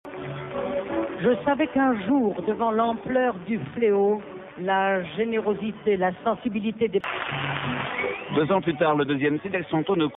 Le son est trop pourrave et trop court !